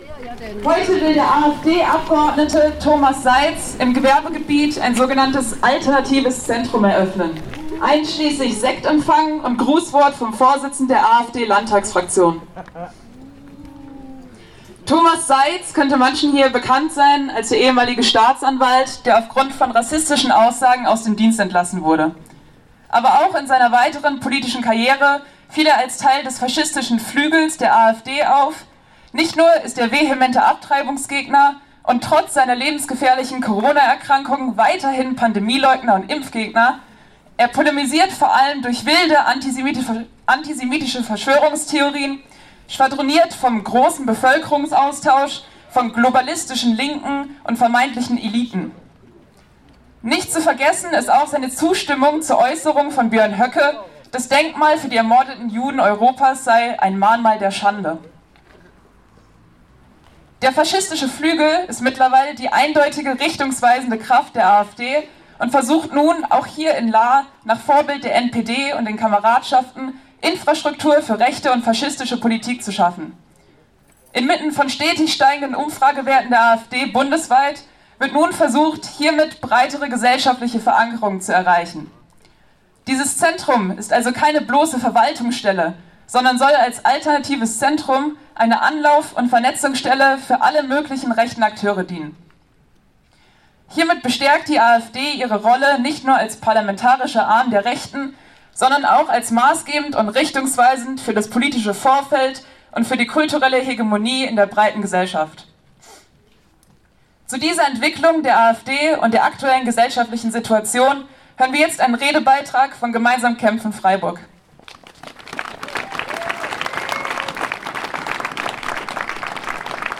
Hier sind einige Eindrücke von Teilnehmenden und die Redebeiträge der Kundgebungen zum Nachhören zu finden:
Redebeitrag von Gemeinsam Kämpfen Freiburg